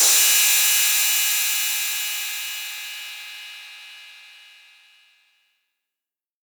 808CY_1_TapeSat_ST.wav